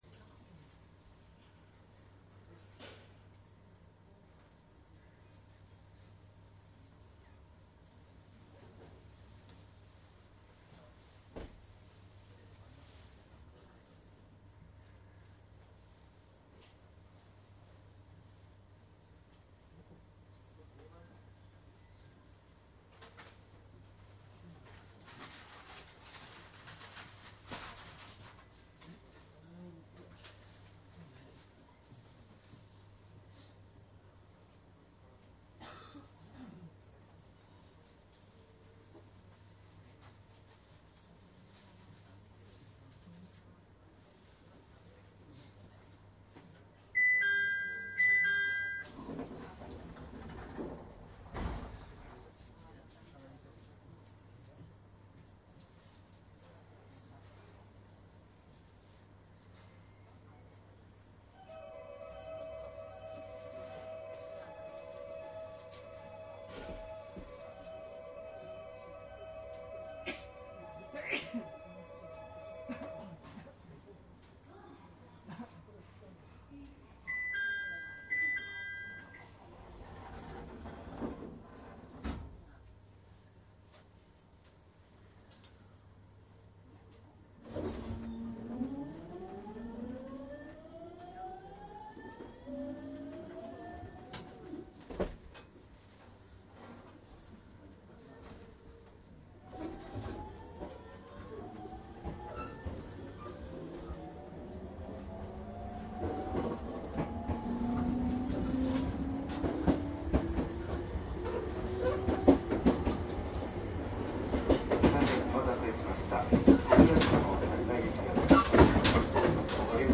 商品説明JR常磐線 701系 走行音 鉄道走行音 ＣＤ ♪
乗務員室助手席側にて収録。幌の軋みや渡り板のカチャカチャした感じが特徴な車端部録音となっていますのでご注意下さい。
■【普通】原ノ町→仙台 クモハ701－102
マスター音源はデジタル44.1kHz16ビット（マイクＥＣＭ959）で、これを編集ソフトでＣＤに焼いたものです。